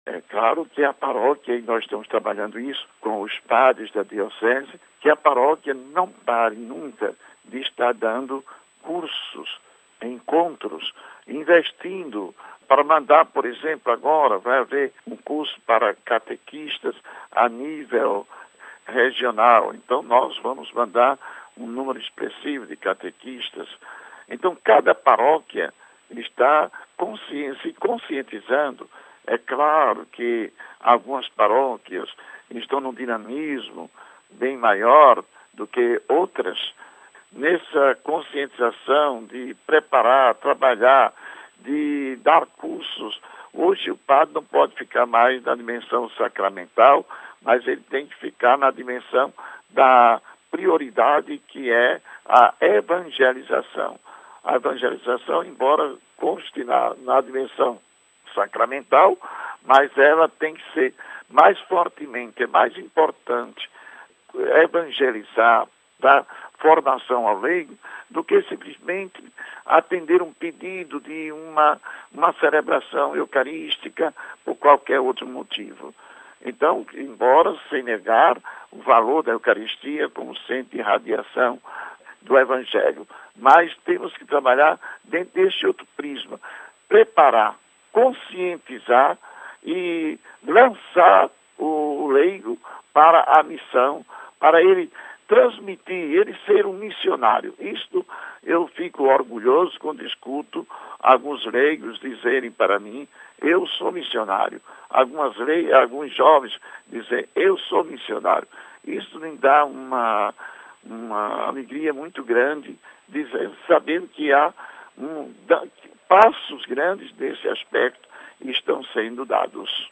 Agradecendo ao bispo de Nazaré pela significativa contribuição, ele nos fala, então, sobre a centralidade da paróquia na formação dos leigos: RealAudio